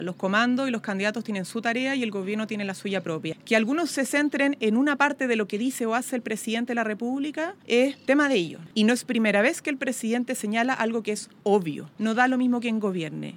La ministra secretaria general de Gobierno, Camila Vallejo, planteó que no se podía “hacer cargo de las incomodidades de un comando”.